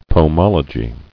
[po·mol·o·gy]